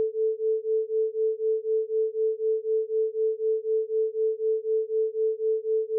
Example 5: 4Hz Binaural Beat
Two tones presented binaurally (438Hz on the left, 442Hz on the right)